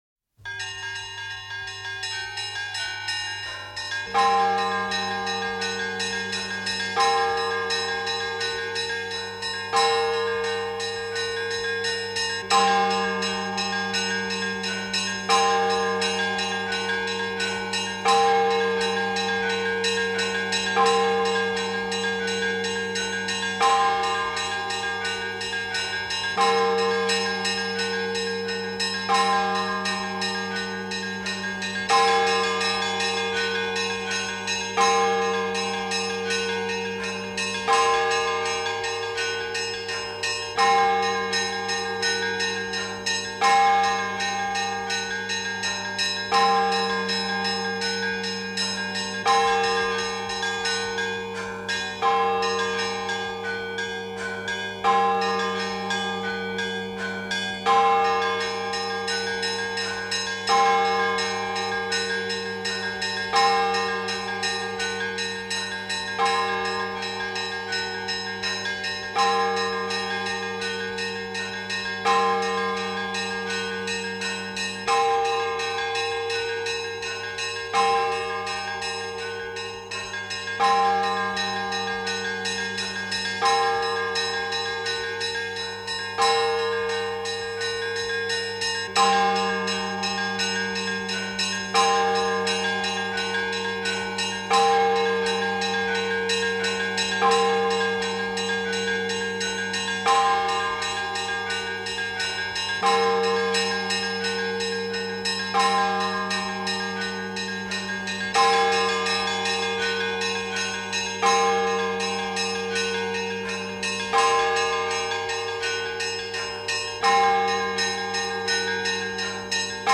Index of /lib/fonoteka/etnic/rossia/kolokola/rostov-2
12_Budnichnyj_Zvon.mp3